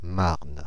The Marne (/mɑːrn/; French pronunciation: [maʁn]
Fr-Paris--Marne.ogg.mp3